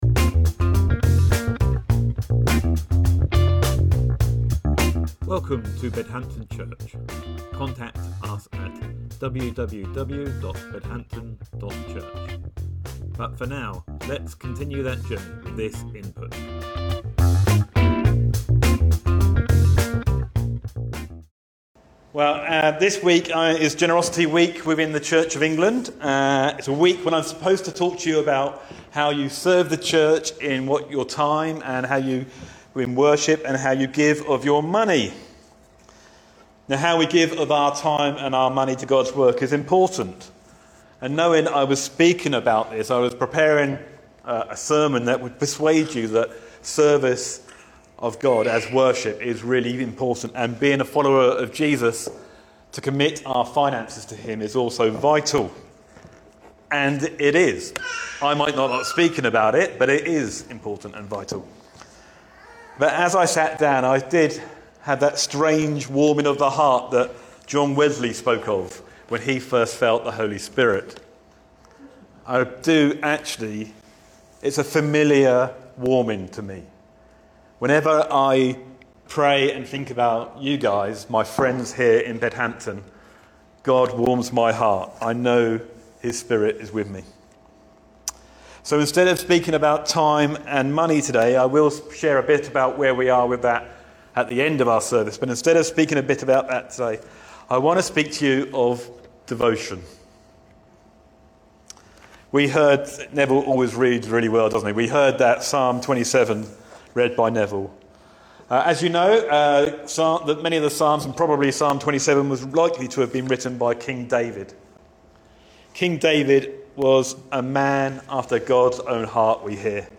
Sermon September 15th, 2024 – Generous devotion